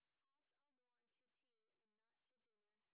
sp14_white_snr30.wav